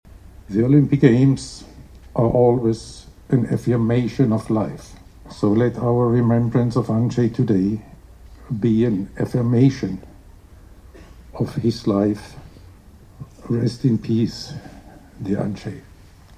- przyznał obecny na uroczystości przewodniczący MKOl Niemiec Thomas Bach.